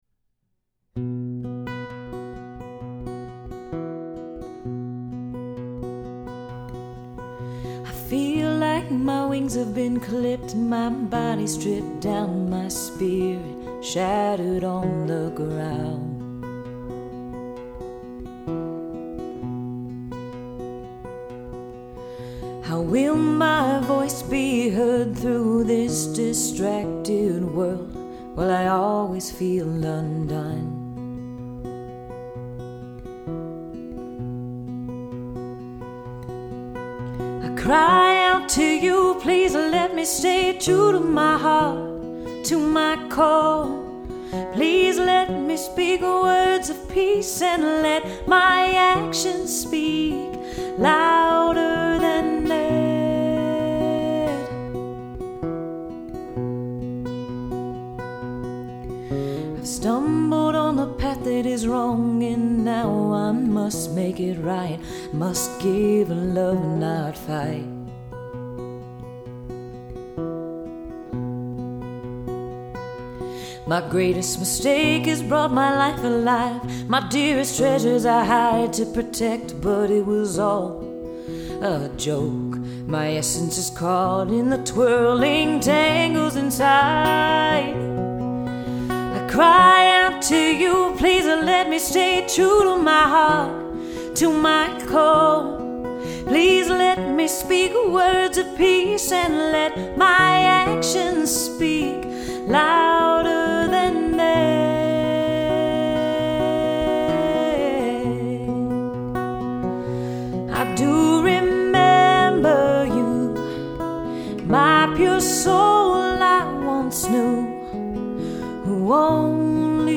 “Restoration” is an acoustic/folk song that has a thoughtful, heartfelt lyric and an appealing melody. This demo is basic, with just vocal and acoustic guitar, but the message and feeling of the song is conveyed very well. A highlight of this demo is the strong, expressive lead vocal performance.